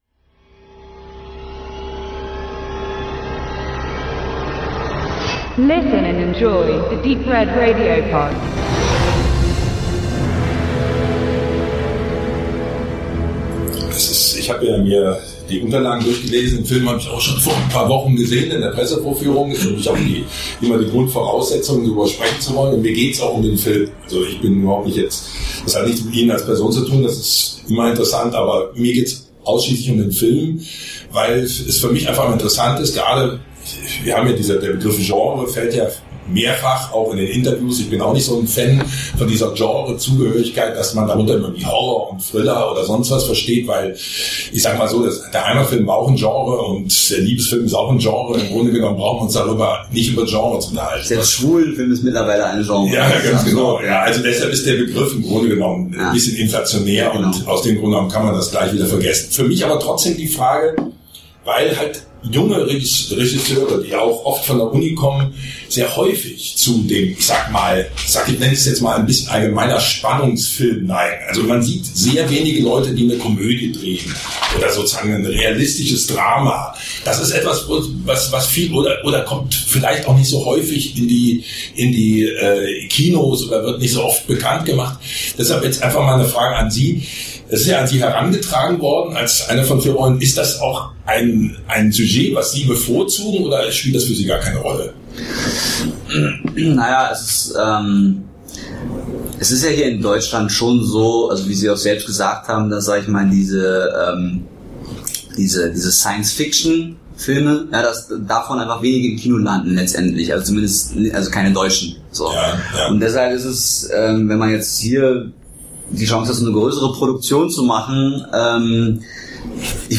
Hinweis: Dies ist ein Archiv-Interview.
Nicht wundern, dass Interview enthielt damals einige Musikstücke. Diese mussten nun wenig professionell, aber dafür reichlich charmant rausgekürzt werden.